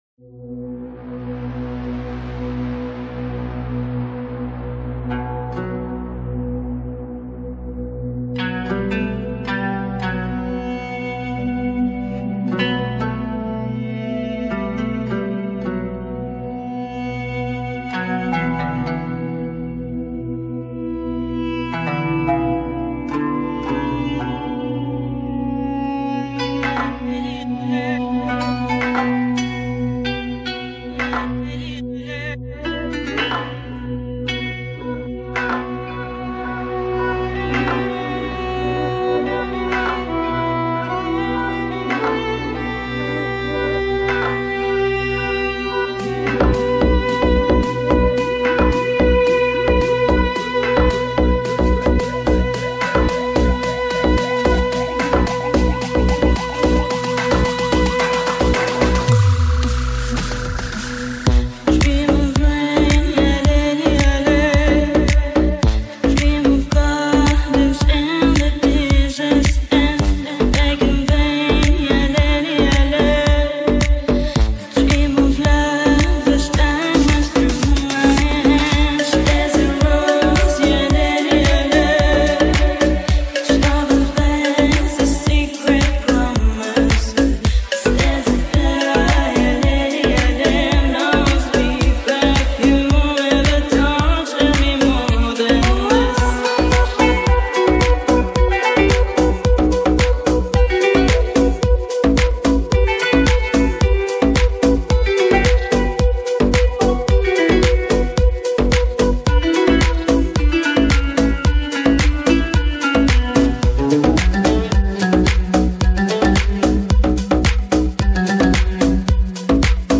Carpeta: musica arabe mp3